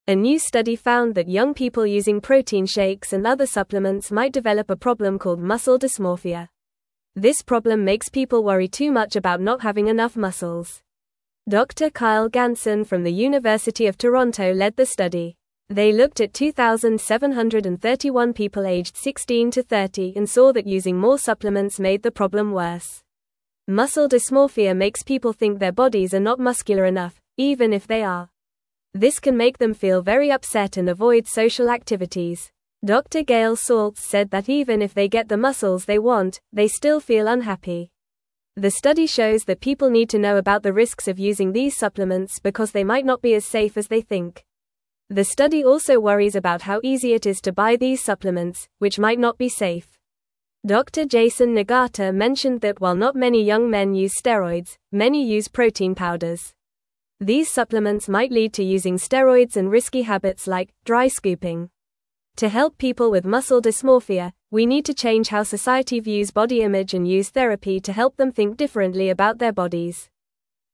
Fast
English-Newsroom-Lower-Intermediate-FAST-Reading-Worrying-About-Muscles-Can-Make-You-Unhappy.mp3